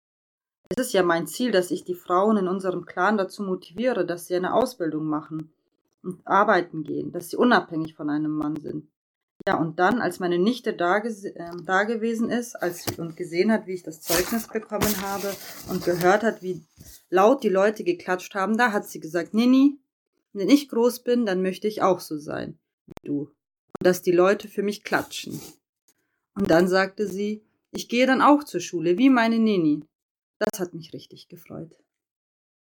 Junge Frauen aus München, mit und ohne Zuwanderungsgeschichte, beschrieben in sehr persönlichen Worten die Bedeutung von Bildung und Berufsausbildung für ihre Biografie.
Produziert wurden die O-Töne speziell für das Fachforum.